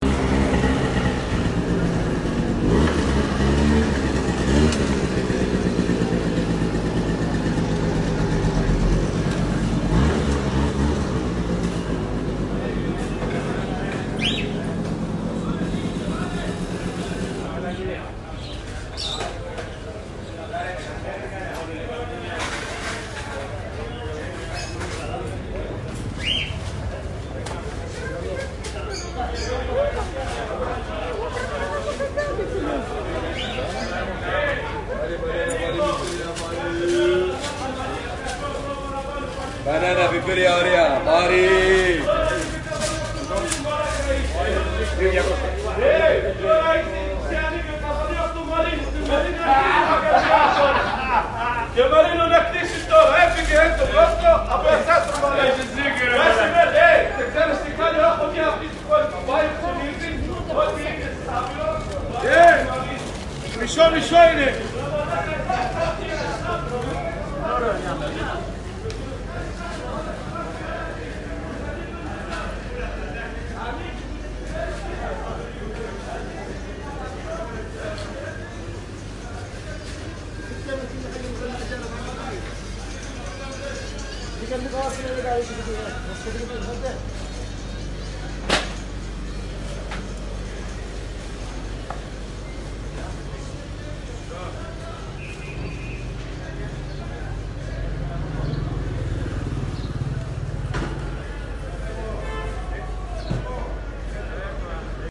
葑门横街菜市场
标签： 苏州 葑门横街 菜市